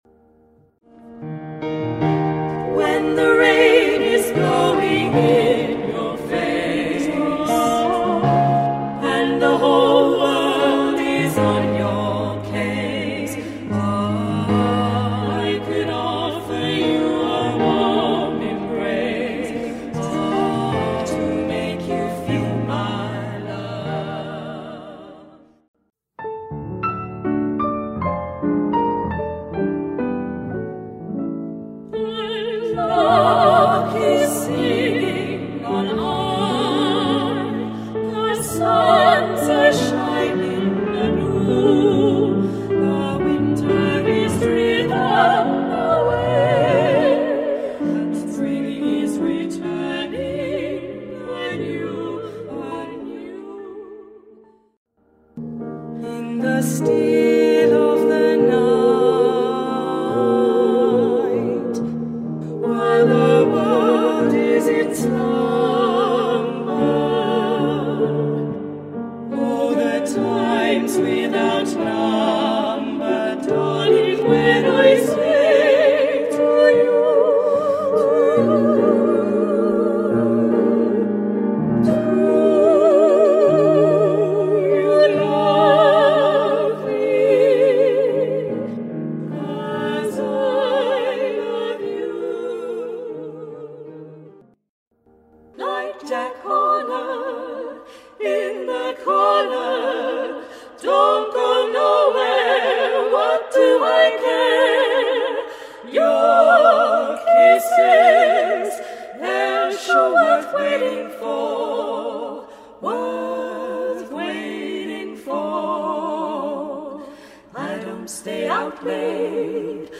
Female Trio
Featuring Traditional Christmas Entertainment